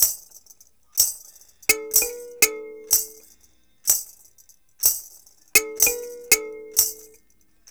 124-PERC3.wav